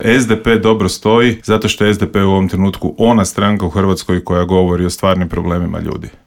Borba se vodi i u Gradu Puli gdje poziciju čelnog čovjeka želi bivši predsjednik SDP-a i saborski zastupnik Peđa Grbin koji je u Intervjuu Media servisa poručio: